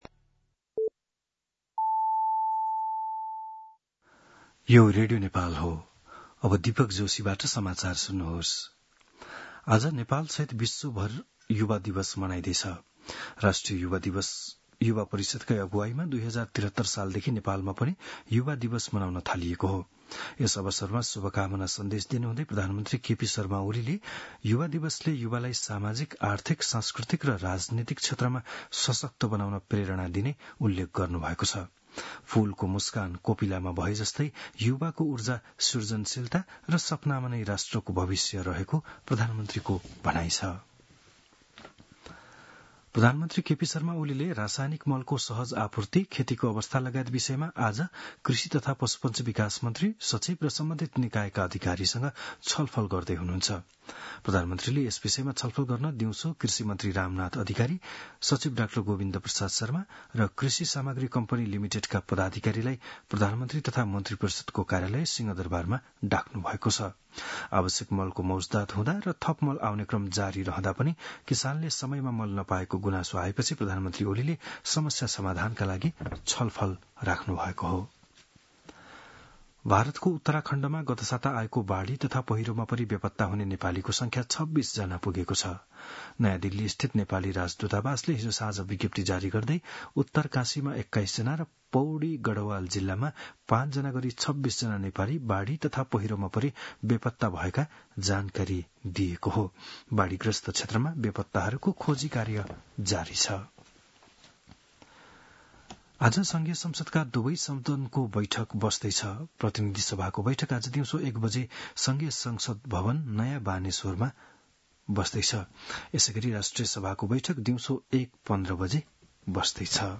बिहान ११ बजेको नेपाली समाचार : २७ साउन , २०८२
11am-News-27.mp3